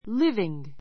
líviŋ